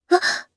Kara-Vox_Damage_jp_03.wav